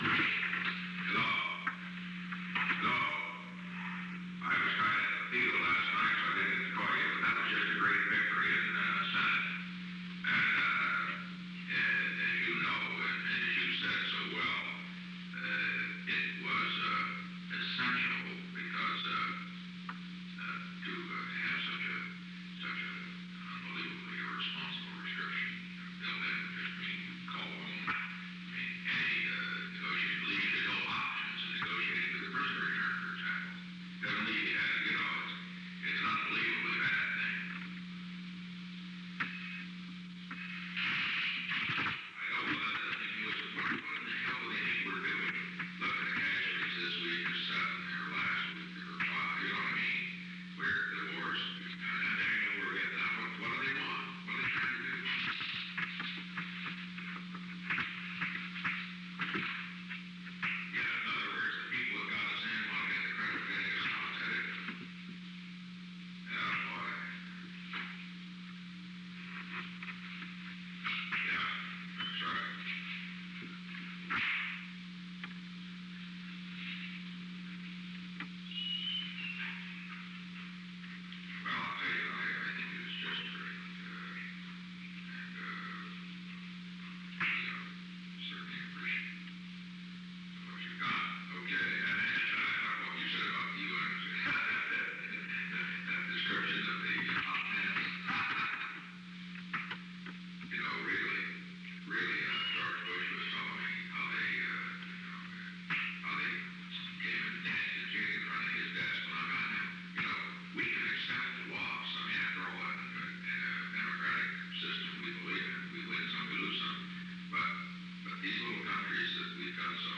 Secret White House Tapes
Conversation No. 607-2
Location: Oval Office
The President talked with Hugh Scott.